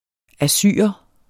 Udtale [ aˈsyˀʌ ]